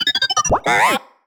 sci-fi_driod_robot_emote_11.wav